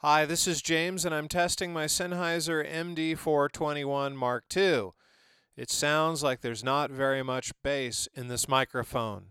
Mind you it doesn't sound "BAD" and the rolloff still works as I click through the various positions.
MD421-Test.mp3